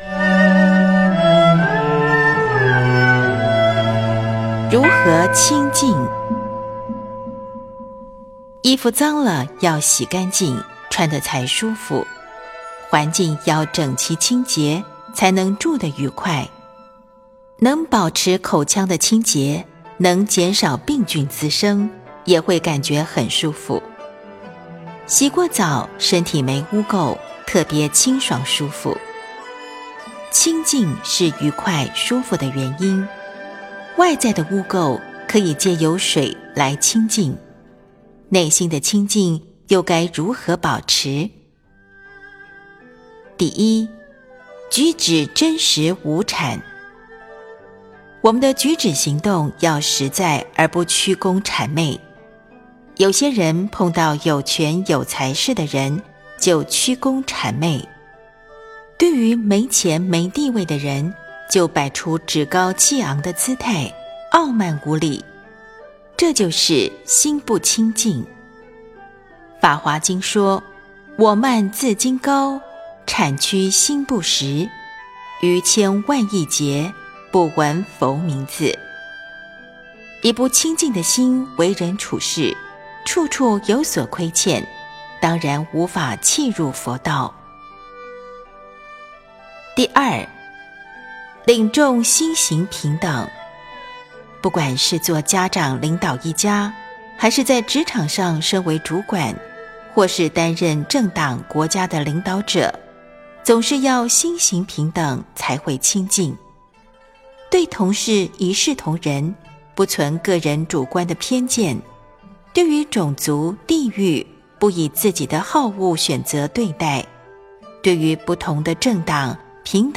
82.如何清净--佚名 冥想 82.如何清净--佚名 点我： 标签: 佛音 冥想 佛教音乐 返回列表 上一篇： 80.察言应对--佚名 下一篇： 85.菩萨的作为--佚名 相关文章 静止世界--佛教音乐(世界禅风篇) 静止世界--佛教音乐(世界禅风篇)...